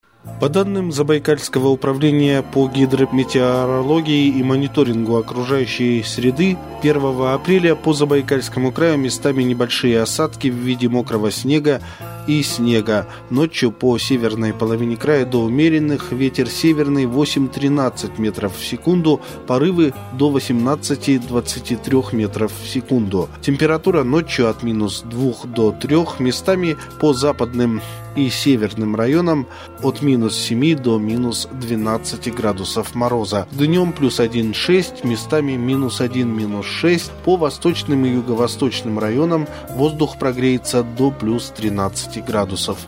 Аудио прогноз погоды на 01.04.2023